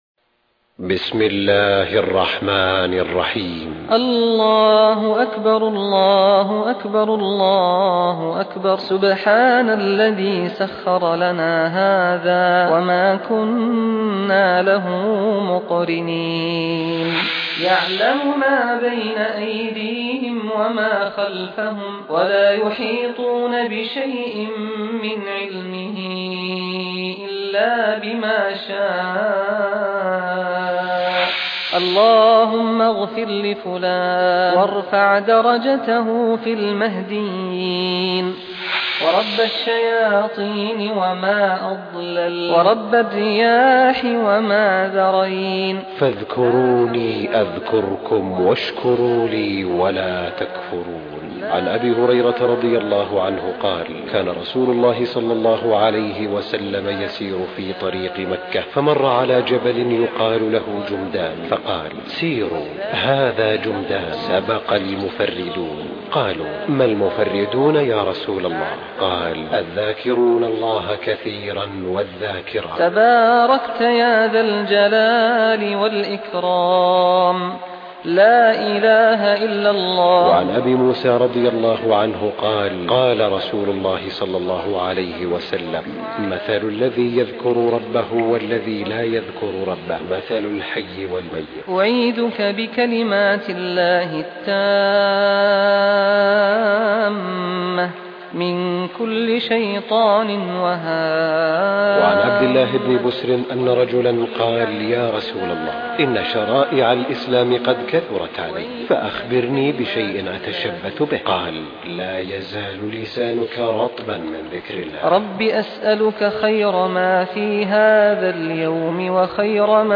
تلاوة مريحة لالأذكار الشاملة (الصباح والمساء) بصوت الشيخ سعد بن سعيد الغامدي.